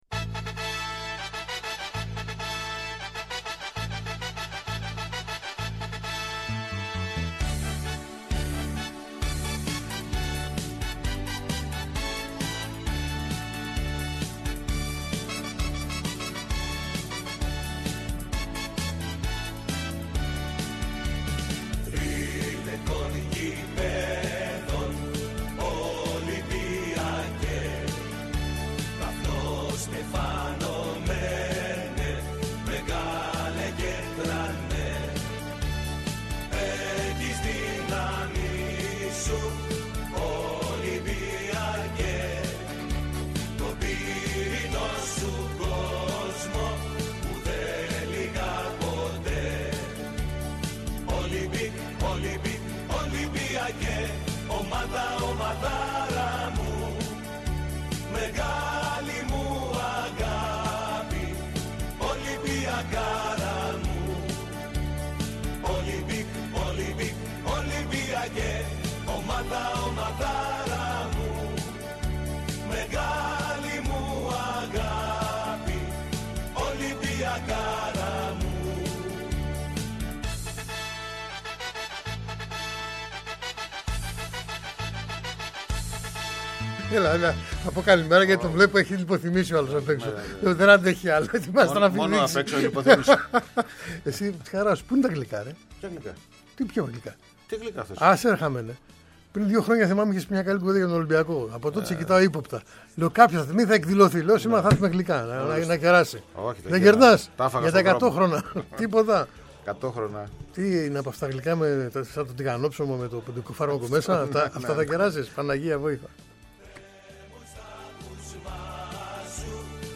Επιτρέπεται μάλιστα η είσοδος στο στούντιο σε κάθε λογής περαστικούς!